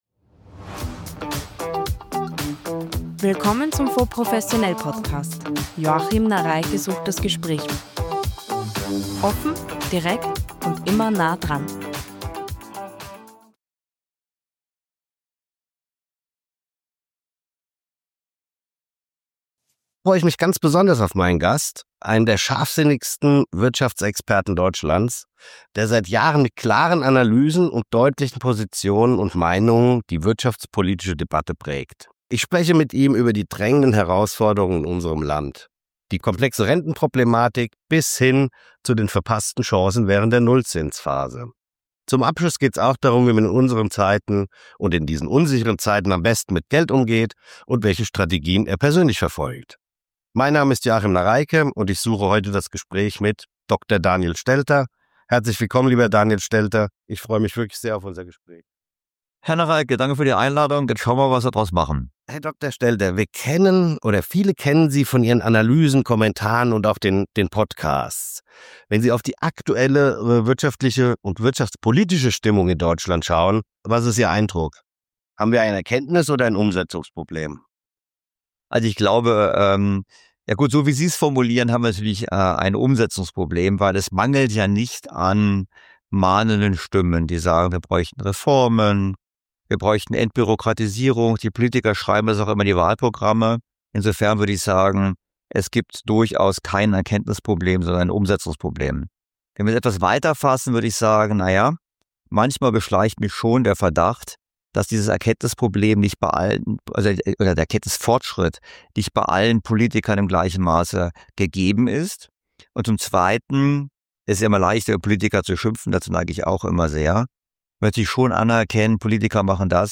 Ein scharfsinniger Dialog über Fehlentwicklungen der Politik – und wie man trotzdem klug mit Geld umgeht.